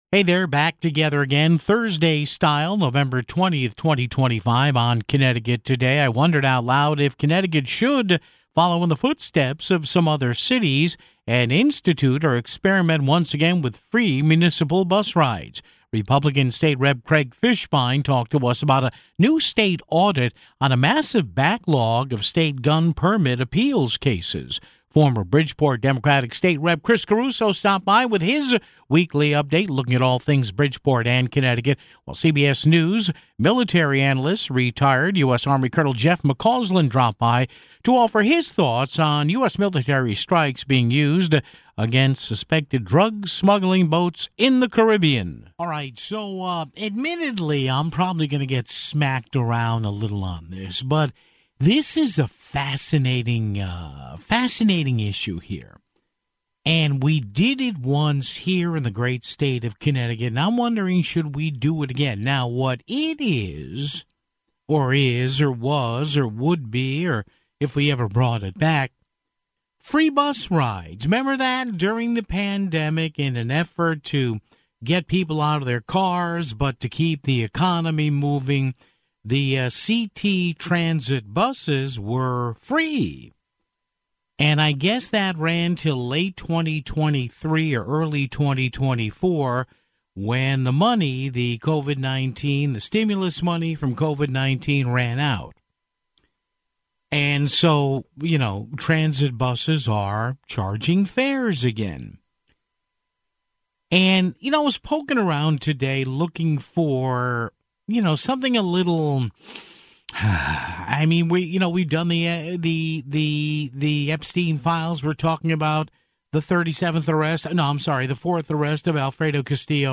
GOP State Rep. Craig Fishbein joined us to talk about a new state audit on a massive backlog of state gun permit appeals cases (14:26).